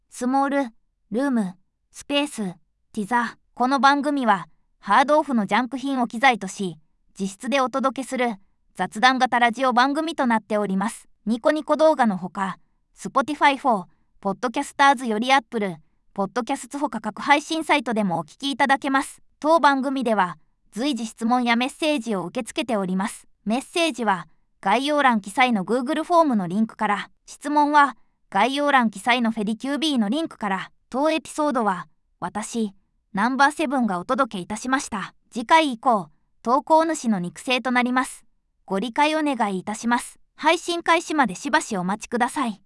当エピソードは、VOICEVOXを使用しました。